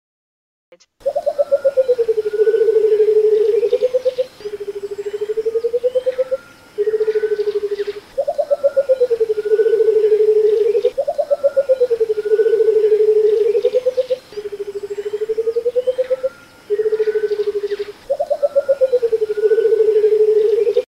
Deze soort niet: Phaesant coucal. Leuk geluid
White-browed coucal.mp3